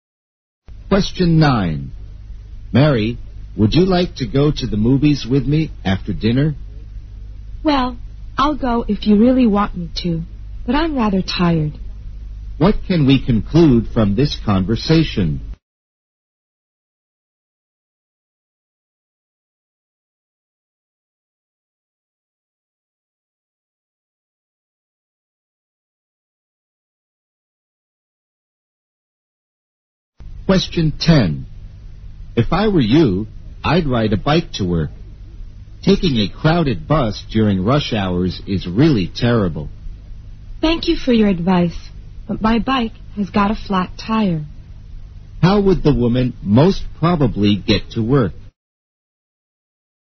在线英语听力室163的听力文件下载,英语四级听力-短对话-在线英语听力室